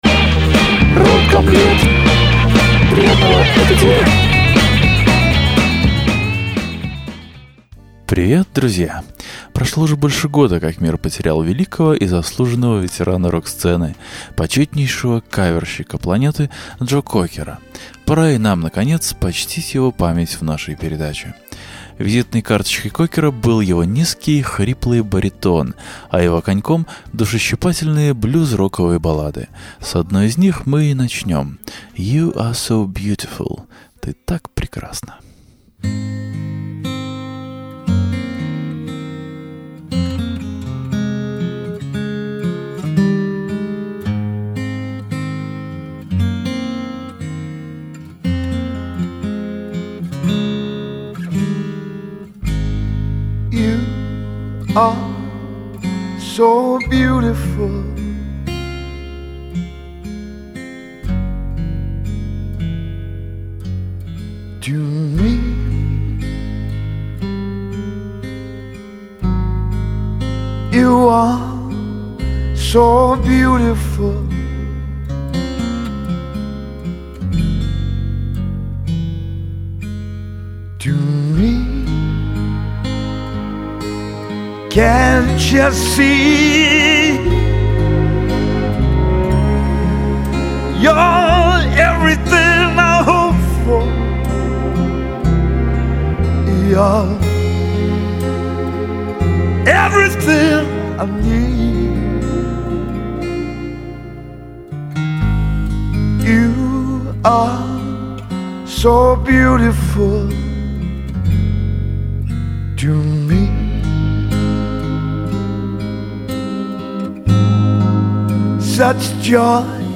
Визитной карточкой Кокера был его низкий хриплый баритон, а его коньком – душещипательные блюз-роковые баллады.